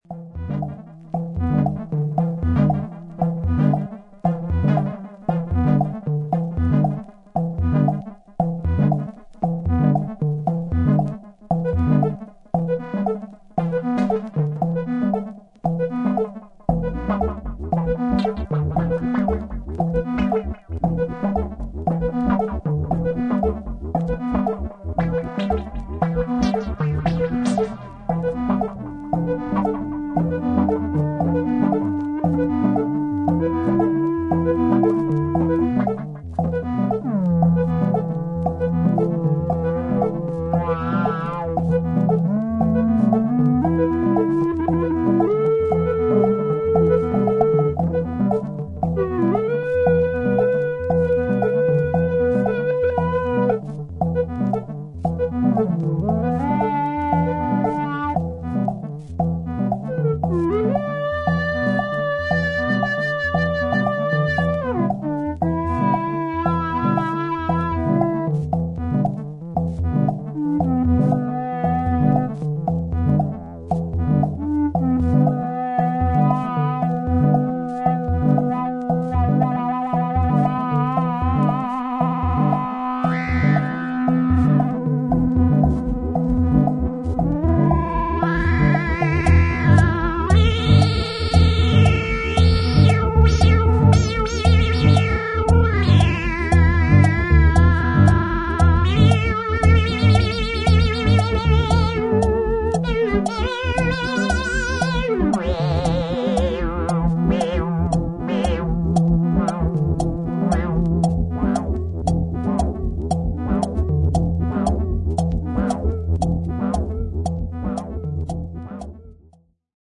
程よいポップさとアンビエンス感を踏まえた